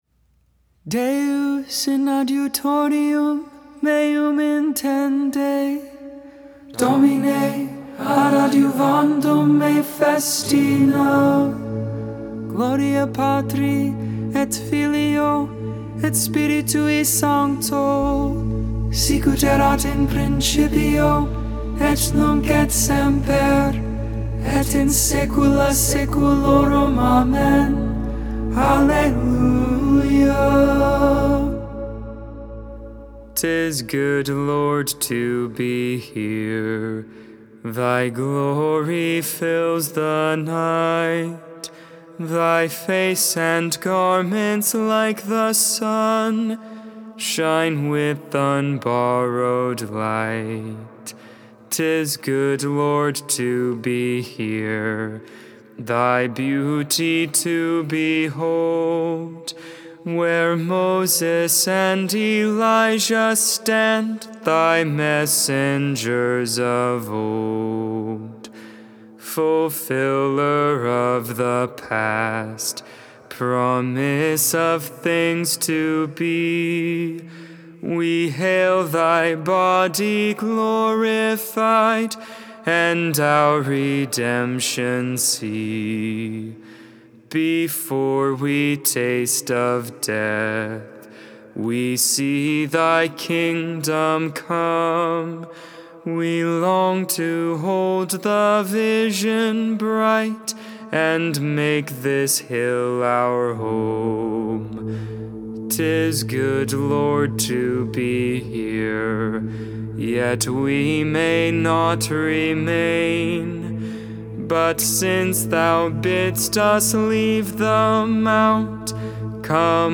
Vespers, Evening Prayer for the 18th Friday of Ordinary Time. August 6th, 2021, on the Feast of the Transfiguration of the Lord.